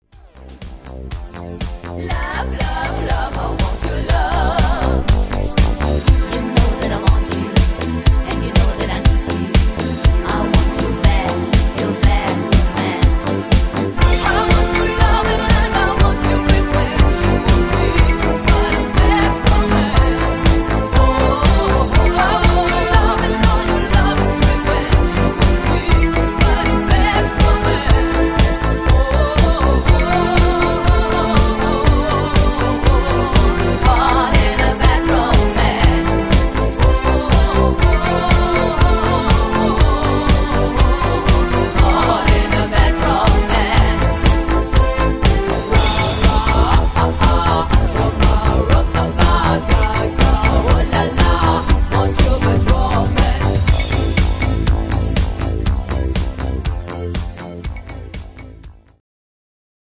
Rhythmus  Disco
Art  Englisch, Pop, Weibliche Interpreten